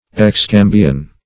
Meaning of excambion. excambion synonyms, pronunciation, spelling and more from Free Dictionary.
Search Result for " excambion" : The Collaborative International Dictionary of English v.0.48: Excambion \Ex*cam"bi*on\, Excambium \Ex*cam"bi*um\, n. [LL. excambium.